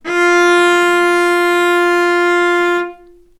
vc-F4-ff.AIF